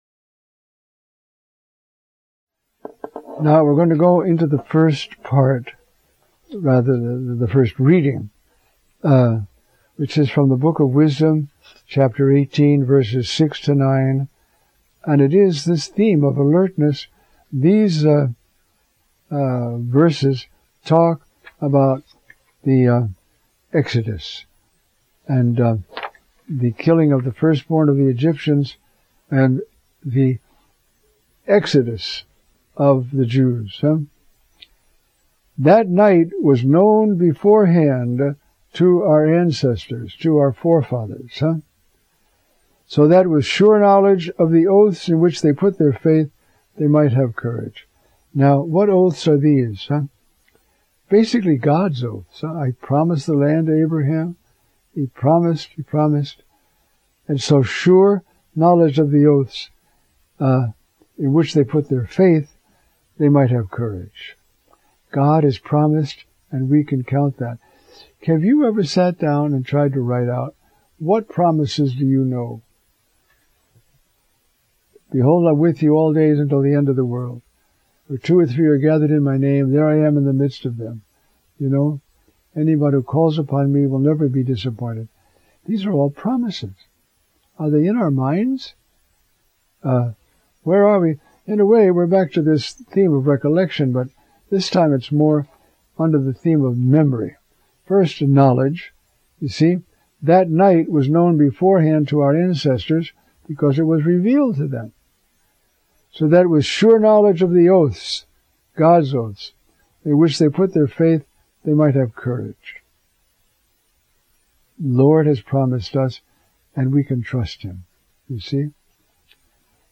Ninteenth Sunday in Ordinary Time Audio Homily